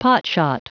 Prononciation du mot potshot en anglais (fichier audio)
potshot.wav